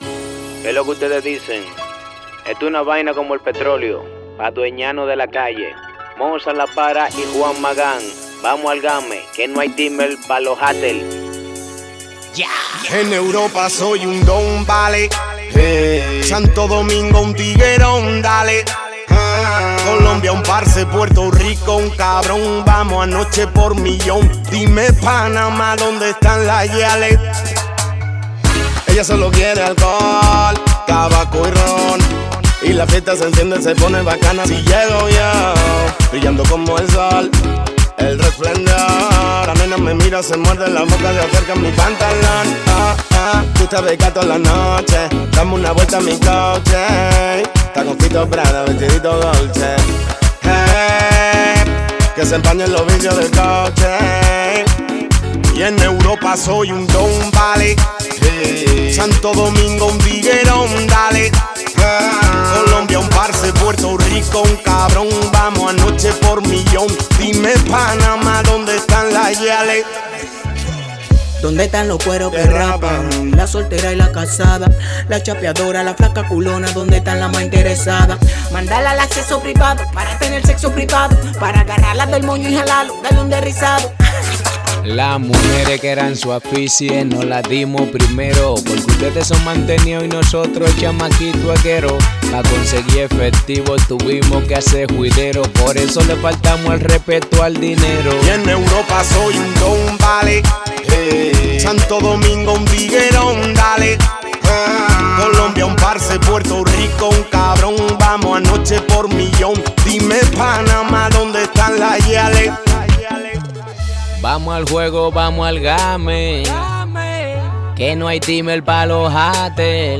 Genres : rap chrétien